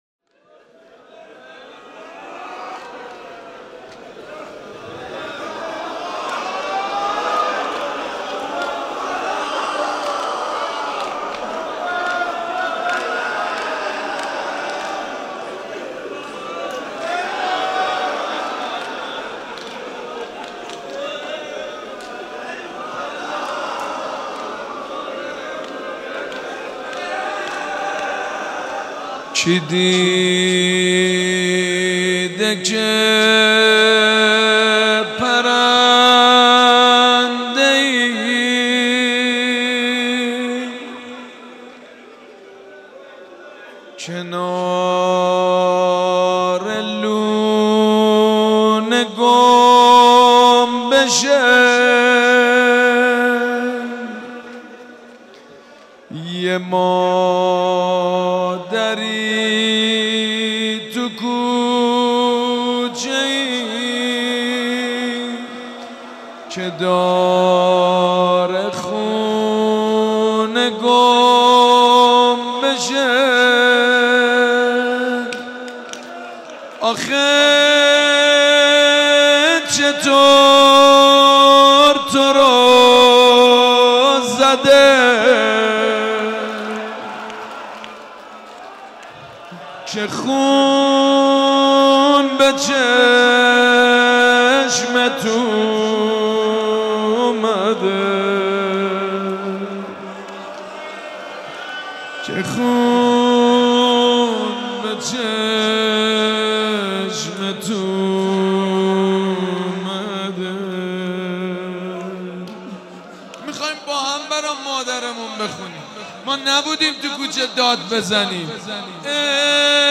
مداحی فاطمیه
روضه